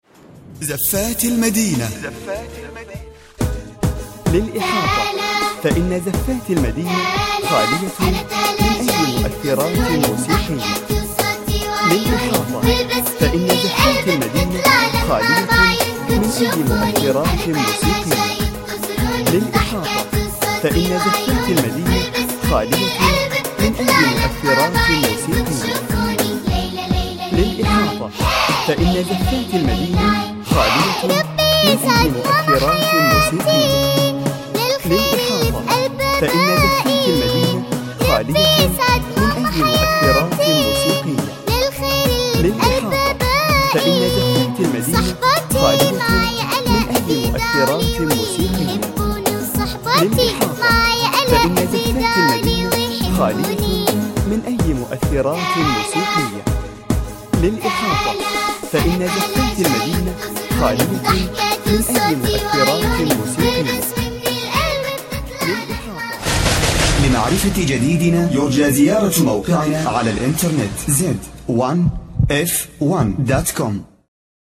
أناشيد